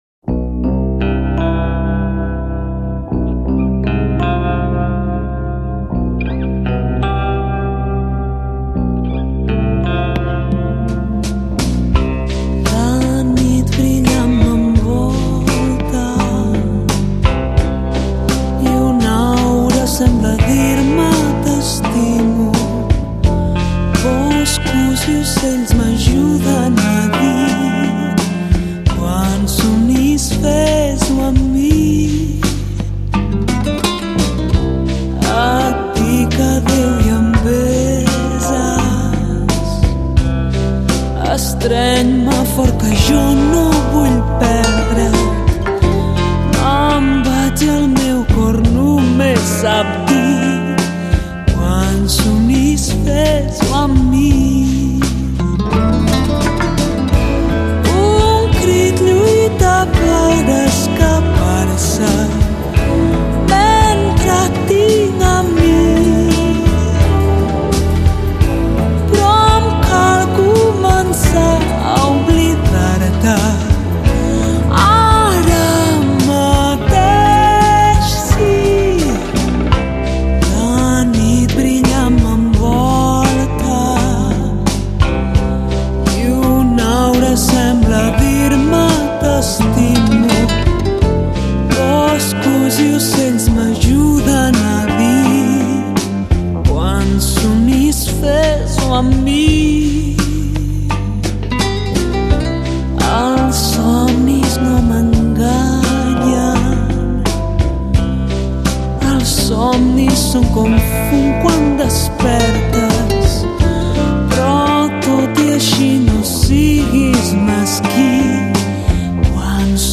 令人崇敬的背景鼓乐，呈现出圣典无尽浩瀚的壮观美